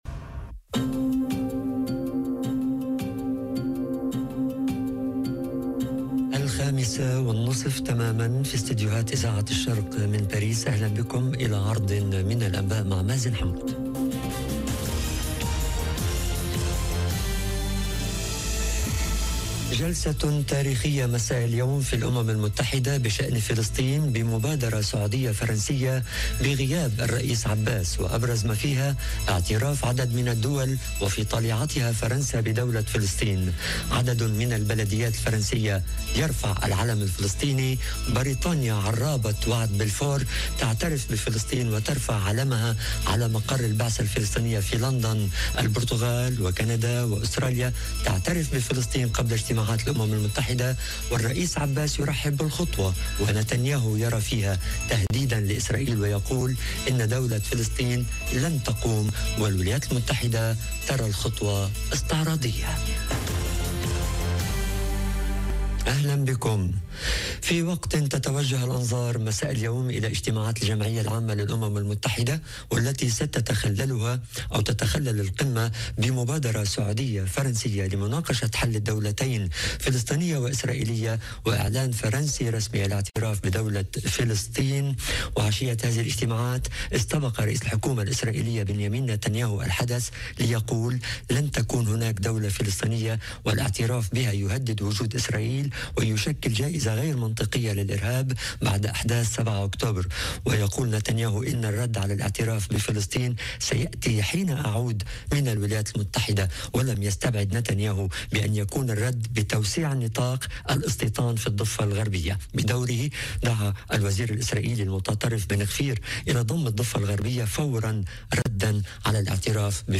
نشرة أخبار المساء: جلسة تاريخية مساء اليوم في الامم المتحدة بشان فلسطين بمبادرة سعودية فرنسية بغياب الرئيس عباس وابرز ما فيها اعتراف عدد من الدول وفي طليعتها فرنسا بدولة فلسطين - Radio ORIENT، إذاعة الشرق من باريس